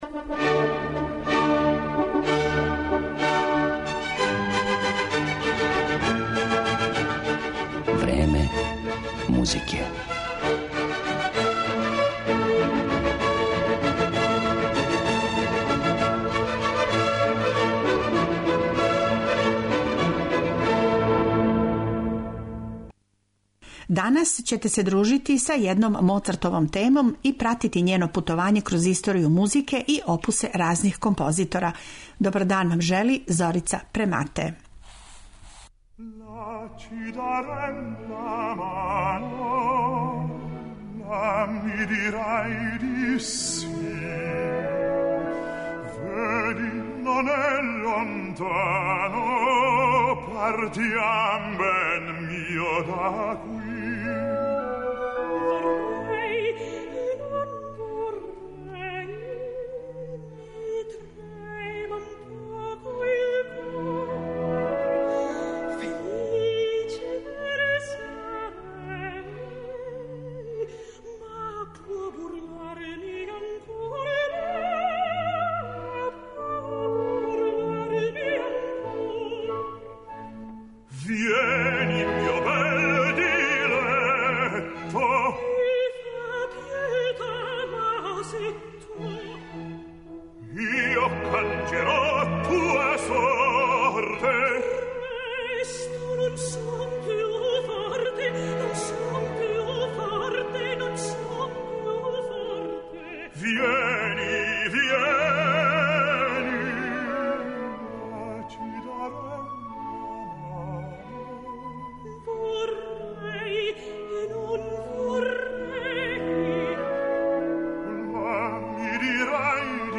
варијација и парафраза